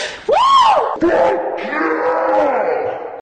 Play, download and share Fuck yeah girl original sound button!!!!
fuck-yeah-girl.mp3